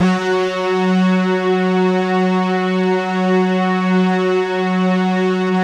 Index of /90_sSampleCDs/Optical Media International - Sonic Images Library/SI1_ObieStack/SI1_OBrass Stabs